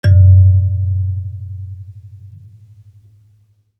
kalimba_bass-F#1-mf.wav